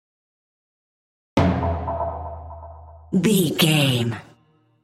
Dramatic Hit Trailer
Sound Effects
Atonal
heavy
intense
dark
aggressive